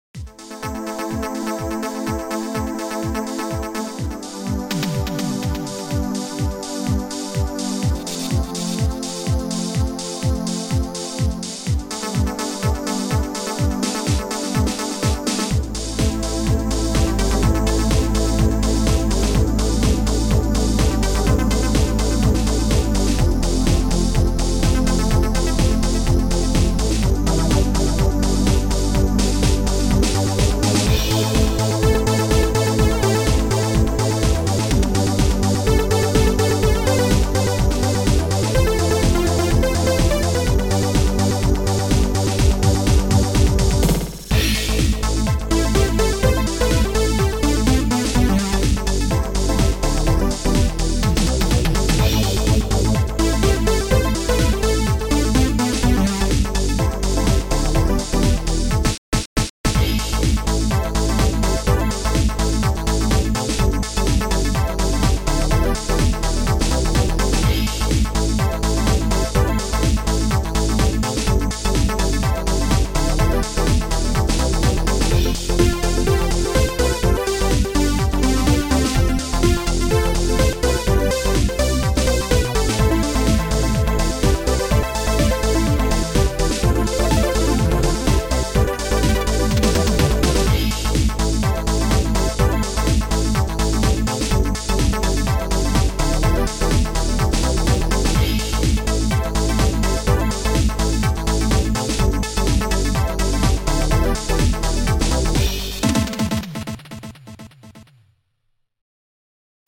Sound Format: Noisetracker/Protracker
Sound Style: Disco Pop / Synth Pop